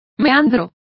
Complete with pronunciation of the translation of meander.